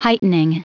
Prononciation du mot heightening en anglais (fichier audio)
Prononciation du mot : heightening